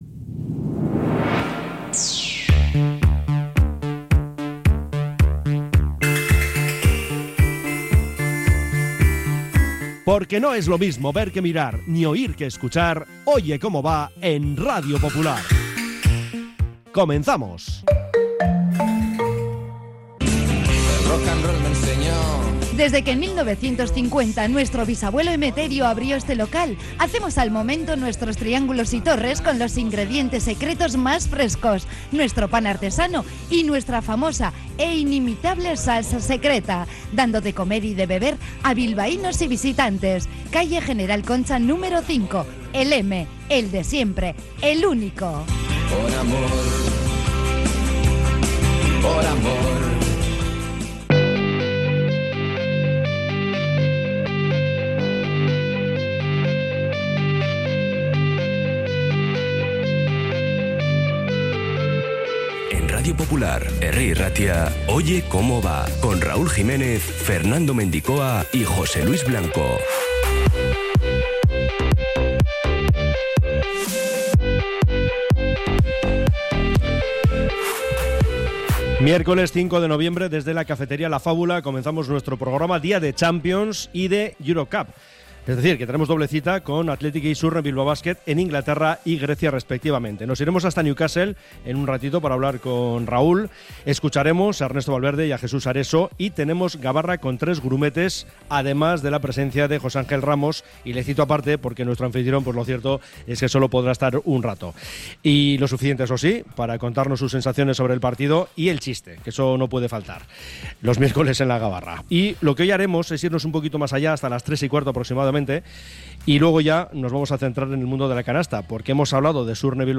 Tramo informativo de 13.30 a 14h